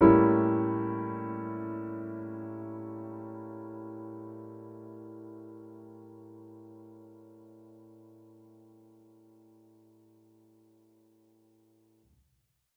Index of /musicradar/jazz-keys-samples/Chord Hits/Acoustic Piano 1
JK_AcPiano1_Chord-A7b9.wav